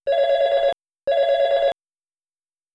La cadencia de campanilla de una llamada interna es 2 rings seguidos y un silencio,
ring interno.wav